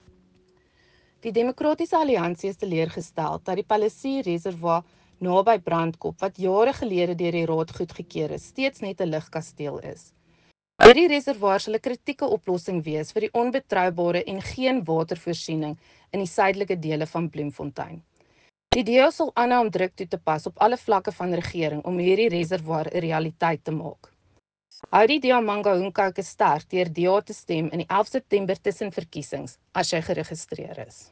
Afrikaans soundbites by Cllr Maryke Davies and